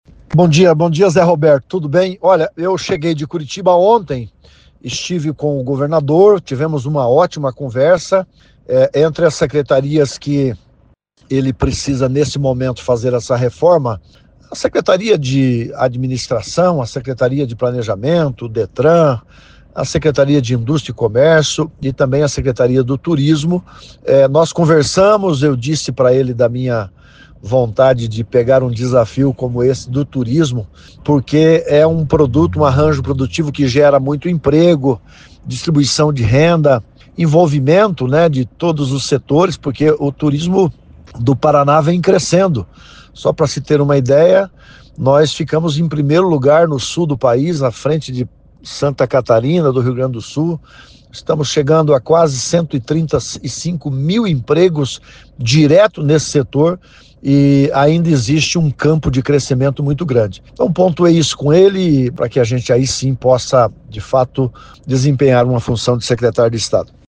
Em entrevista à CBN nesta sexta-feira (21), o ex-prefeito de Cascavel, Leonaldo Paranhos, destacou que a conversa com o governador Ratinho Júnior foi muito boa, e que está pronto para conduzir a secretaria do Turismo, a partir dessa minirreforma promovida pelo chefe do Executivo estadual e que deve ser concluída até a próxima segunda-feira (24).